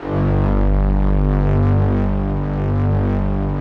SPCSTR. G1-L.wav